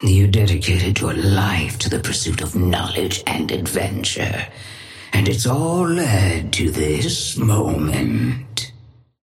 Sapphire Flame voice line - You dedicated your life to the pursuit of knowledge and adventure, and it's all led to this moment.
Patron_female_ally_kelvin_start_02.mp3